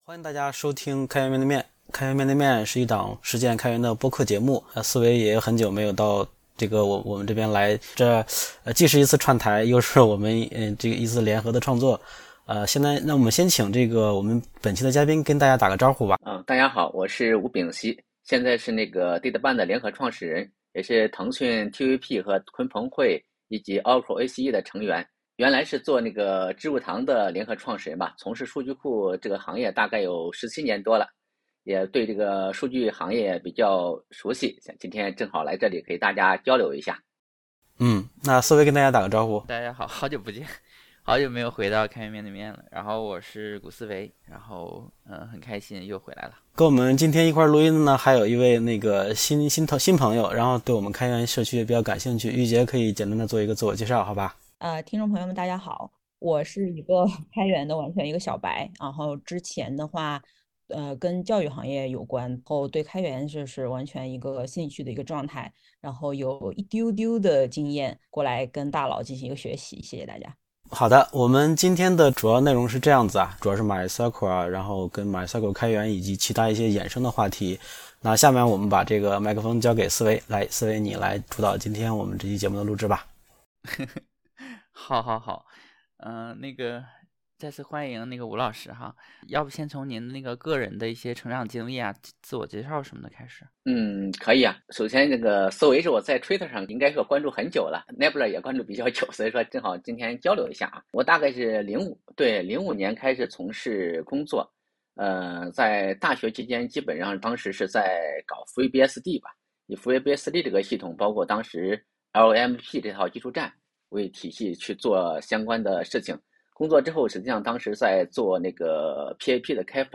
嘉宾